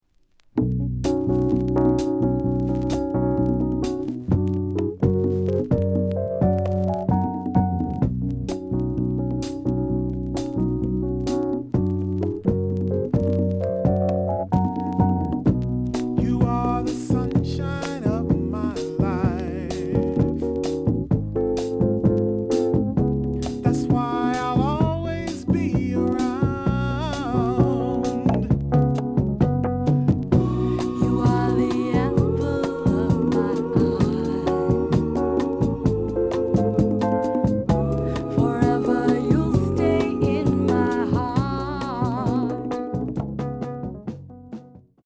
The audio sample is recorded from the actual item.
●Genre: Soul, 70's Soul
Looks good, but slight noise on beginning of A side.